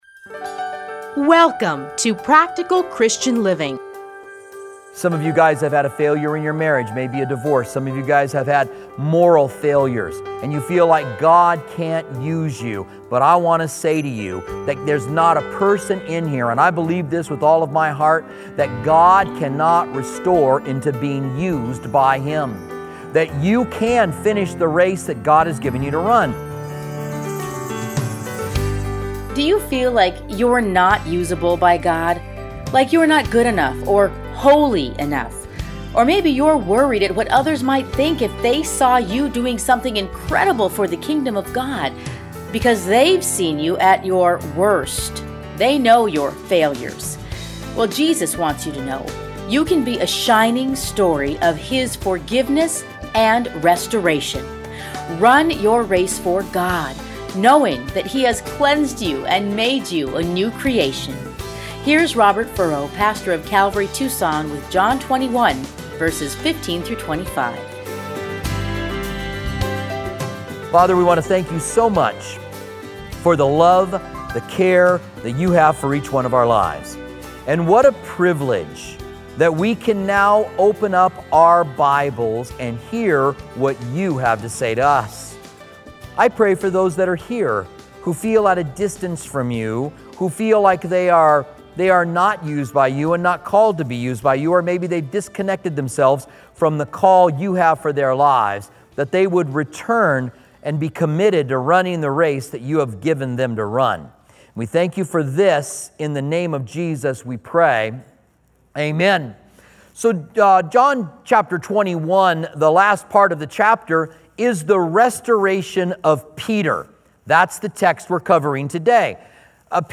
radio programs